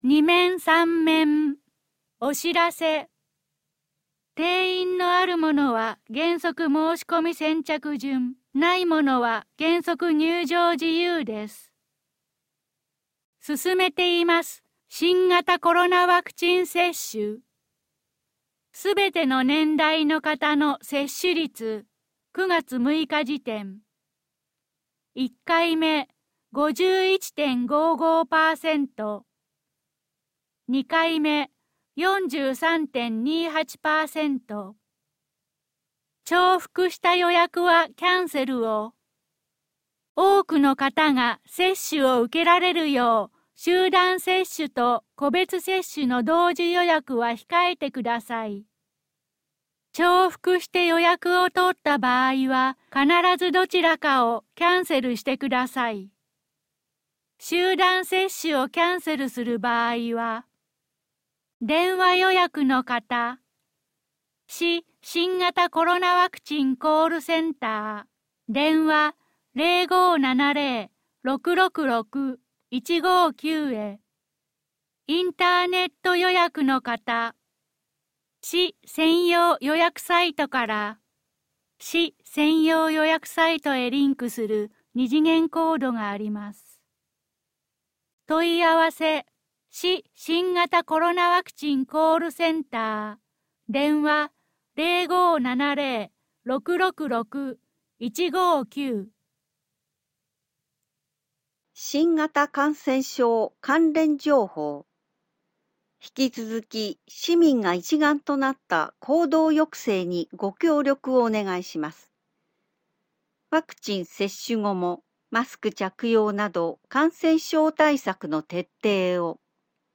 （9月15日午前10時15分に更新） 声の広報（概要版） 概要版1面（MP3／3MB） 概要版2・3面（MP3／17MB） テキスト版 全面（TXT/28KB） 紙面アンケート 今後の紙面作成の参考とするため、アンケートにご協力をお願いします。